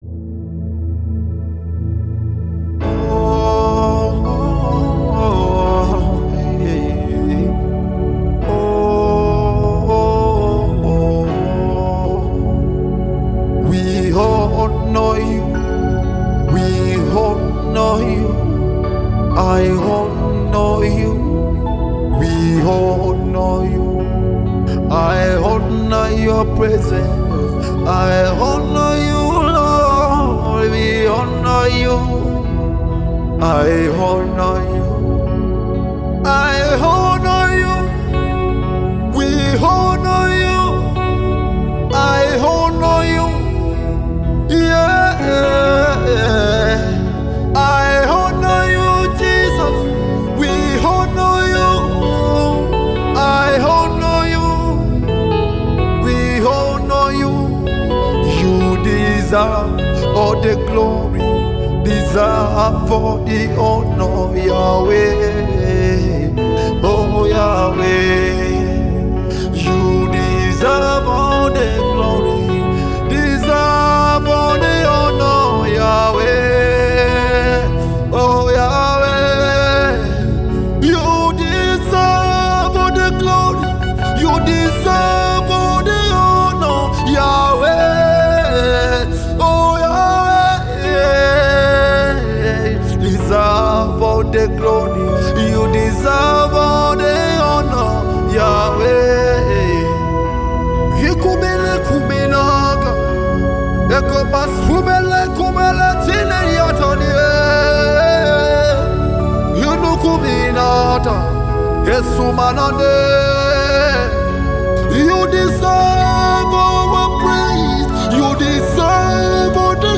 musicWorship
gospel song